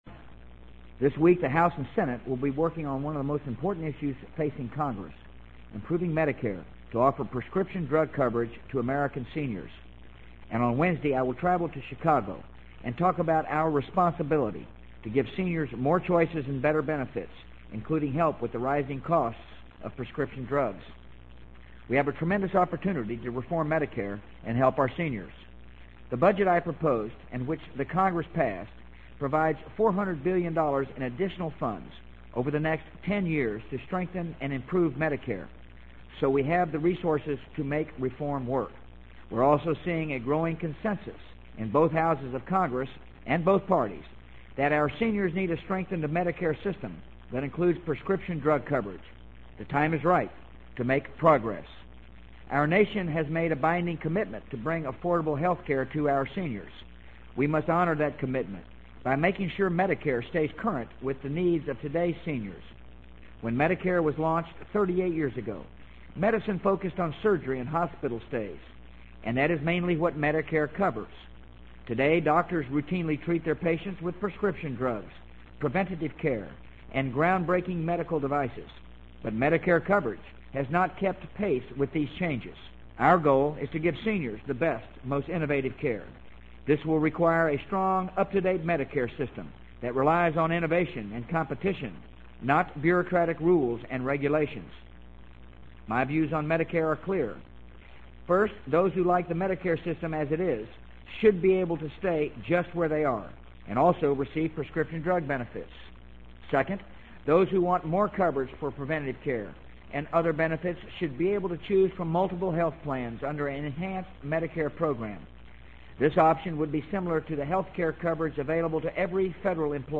【美国总统George W. Bush电台演讲】2003-06-07 听力文件下载—在线英语听力室